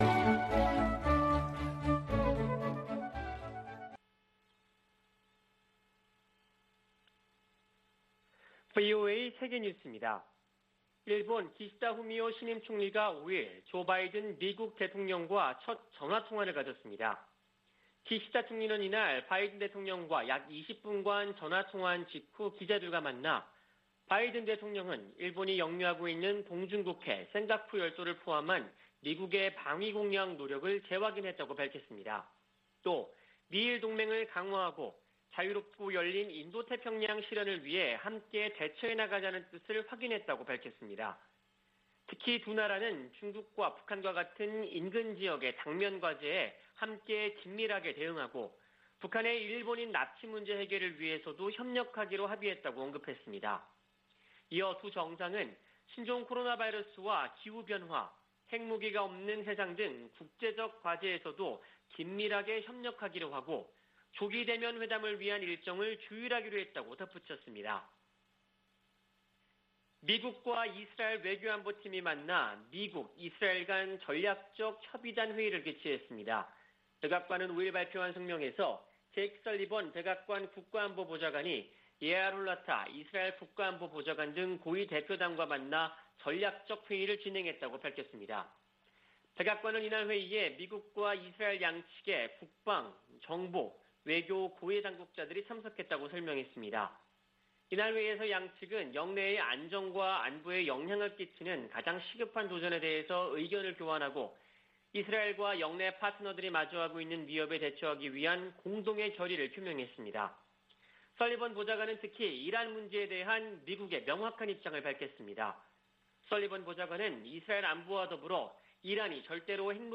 VOA 한국어 아침 뉴스 프로그램 '워싱턴 뉴스 광장' 2021년 10월 6일 방송입니다. 유엔 안보리 전문가패널이 북한의 제재 위반 사례를 담은 보고서를 공개했습니다. 미 국무부는 북한이 안보리 긴급회의 소집을 비난한 데 대해 유엔 대북제재의 완전한 이행 필요성을 강조했습니다. 문재인 한국 대통령은 남북한 체재경쟁이나 국력 비교는 의미 없어진 지 오래라며 협력 의지를 밝혔습니다.